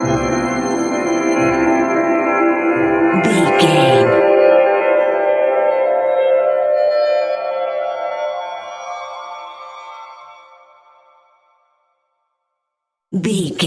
Short musical SFX for videos and games.,
Sound Effects
Ionian/Major
aggressive
bright
epic
futuristic
industrial
intense
driving
bouncy
energetic
funky
heavy
mechanical